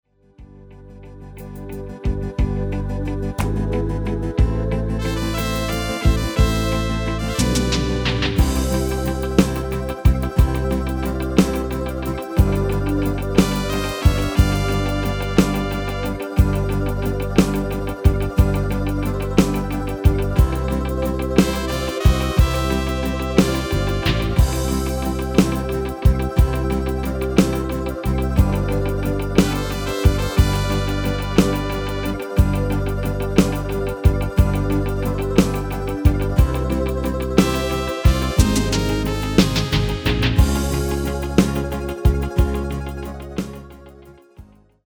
Midifile gearrangeerd in de stijl van:
Genre: Duitse Schlager
Toonsoort: C
Demo's zijn eigen opnames van onze digitale arrangementen.